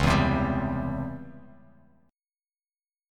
C#mM7b5 chord